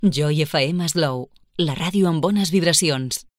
Indicatiu amb la freqüència d'FM.